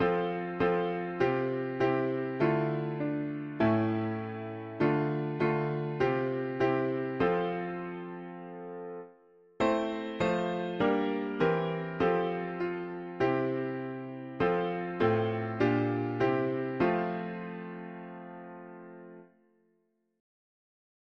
Key: F major Meter: 65.65